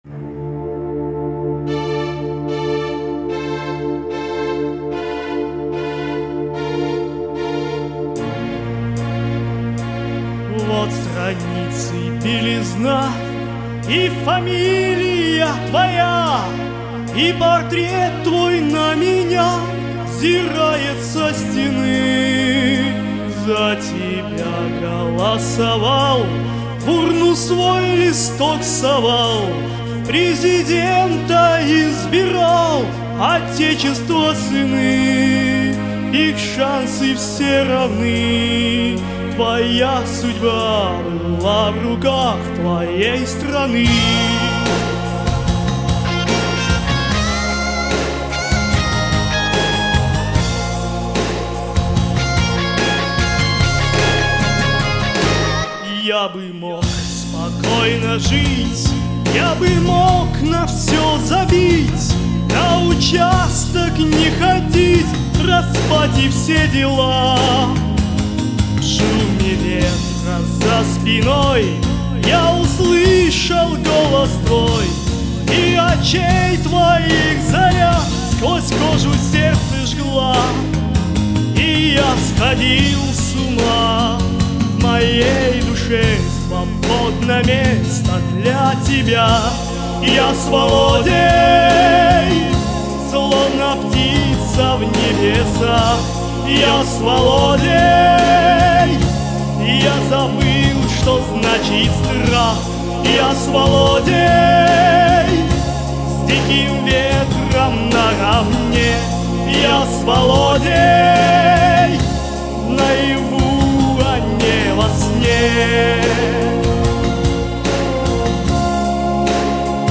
Мне особенно нравится финальный припев... cool
переделка